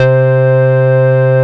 SYN  ANA.21.wav